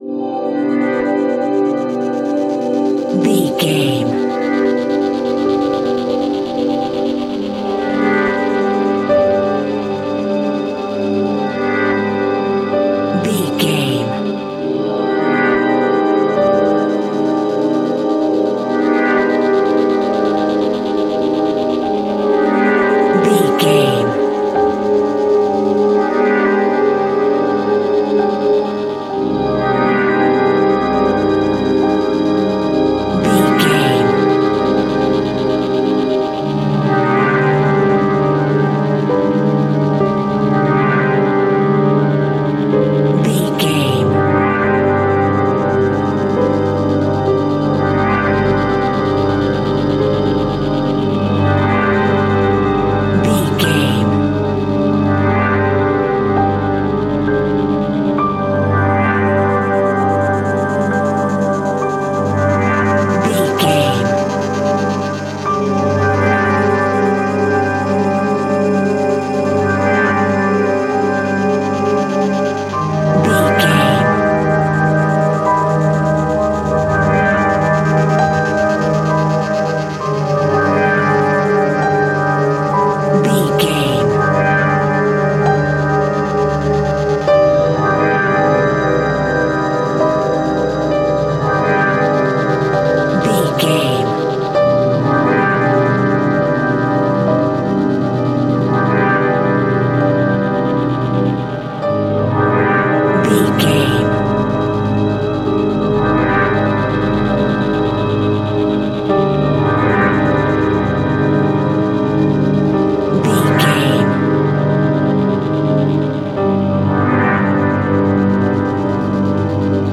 Aeolian/Minor
Slow
ominous
eerie
piano
synthesiser
dark ambience
Synth Pads
Synth Ambience